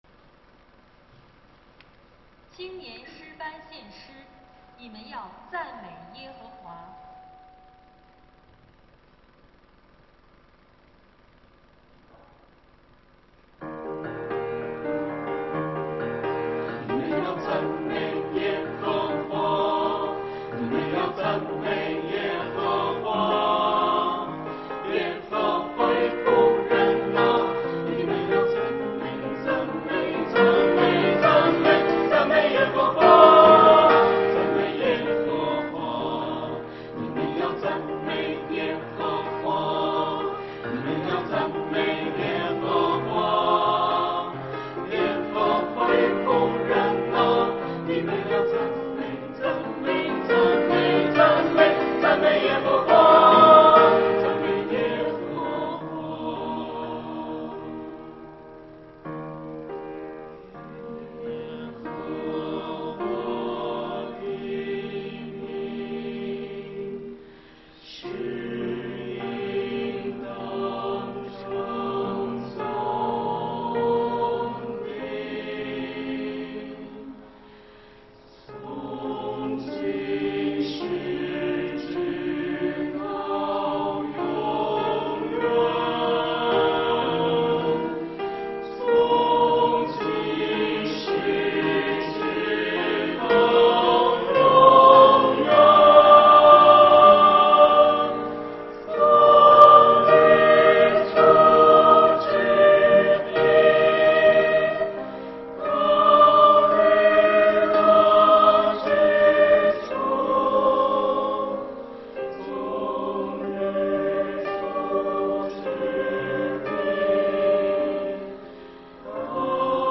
[2021年10月17日主日献唱]《你们要赞美耶和华》 | 北京基督教会海淀堂
团契名称: 青年诗班 新闻分类: 诗班献诗 音频: 下载证道音频 (如果无法下载请右键点击链接选择"另存为") 视频: 下载此视频 (如果无法下载请右键点击链接选择"另存为")